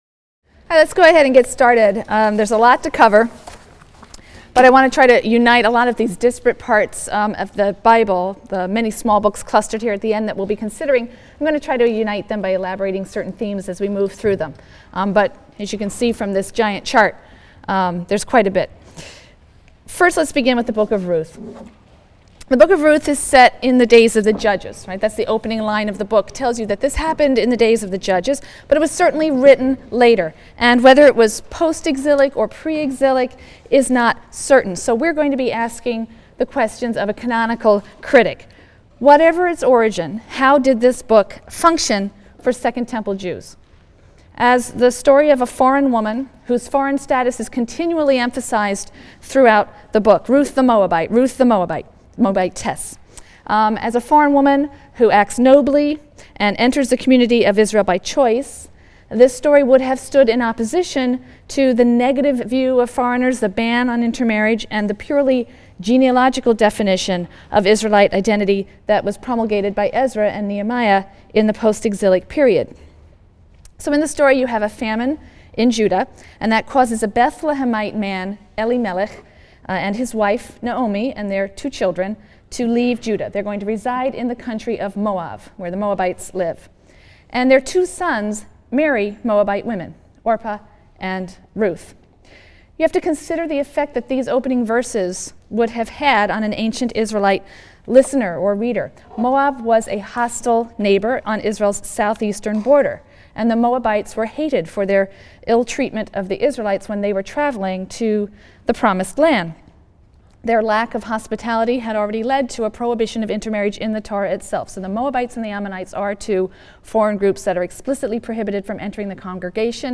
RLST 145 - Lecture 23 - Visions of the End: Daniel and Apocalyptic Literature | Open Yale Courses